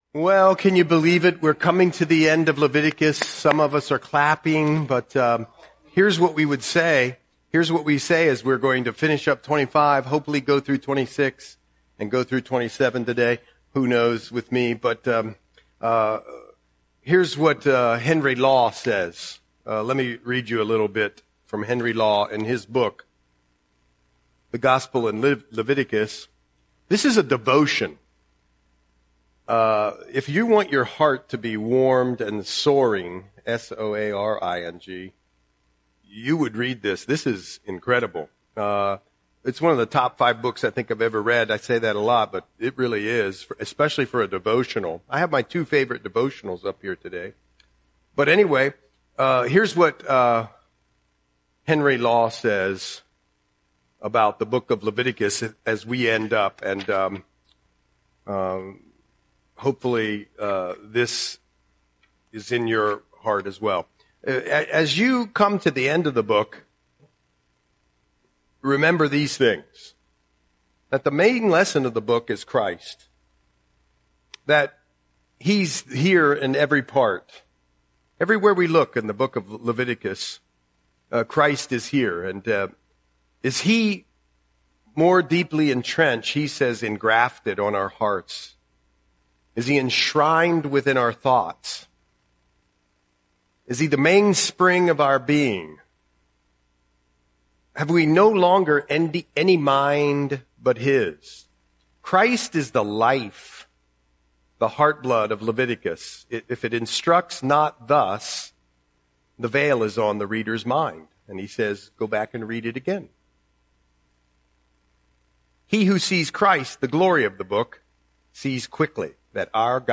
Audio Sermon - January 28, 2026